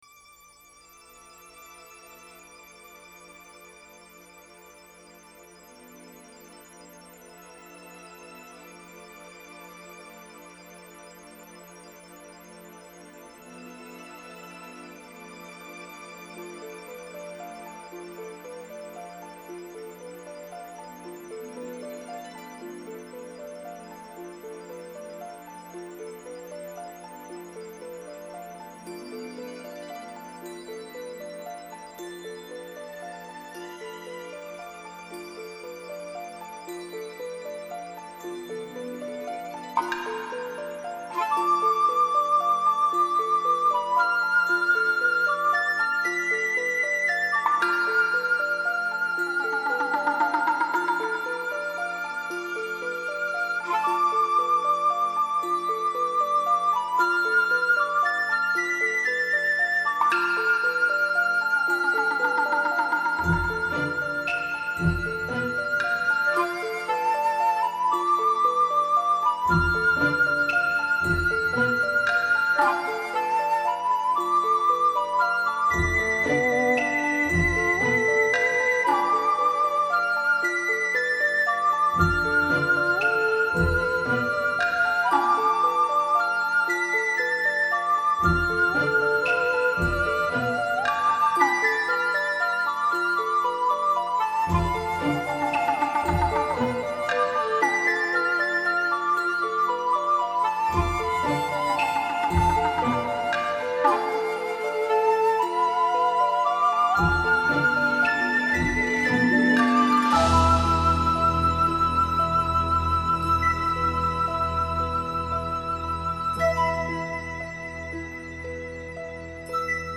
New age
Нью эйдж